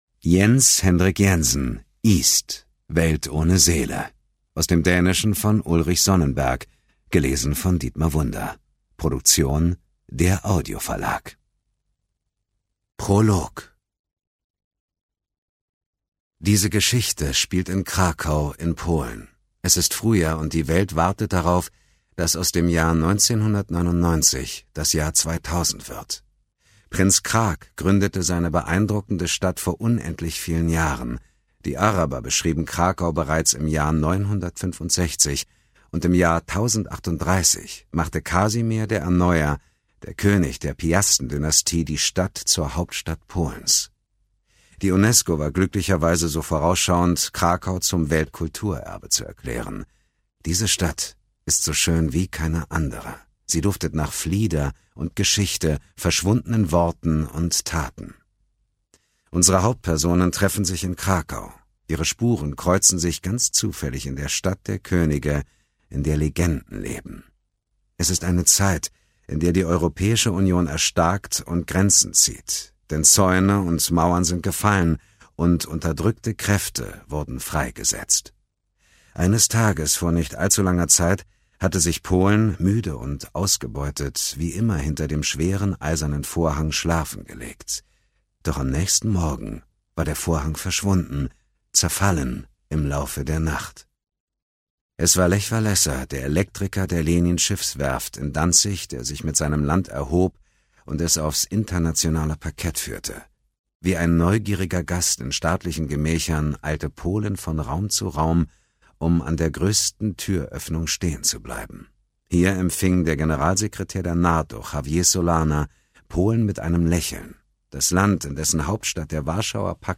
Ungekürzte Lesung mit Dietmar Wunder (2 mp3-CDs)
Dietmar Wunder (Sprecher)
Dietmar Wunder ist bekannt als die Stimme von Daniel Craig in der Rolle des James Bond.